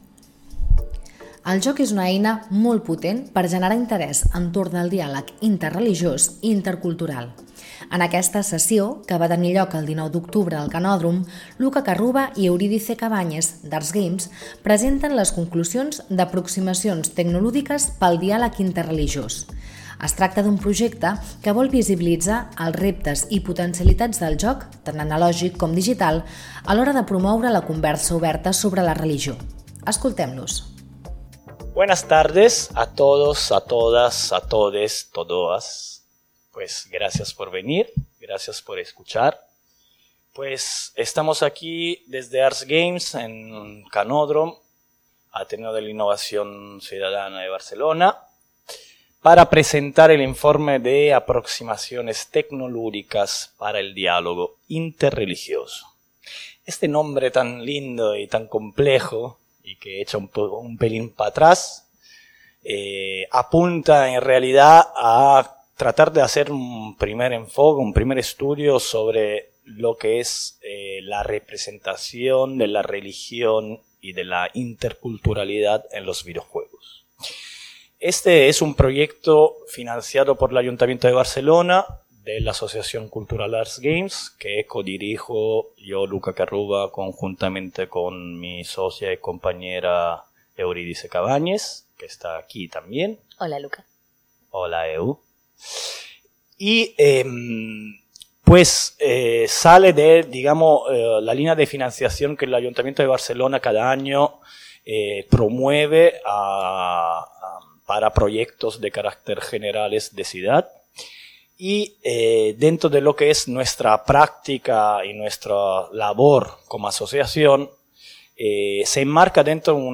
En aquesta sessió, que va tenir lloc el 19 d’octubre de 2021 al Canòdrom – Ateneu d’Innovació Digital i Democràtica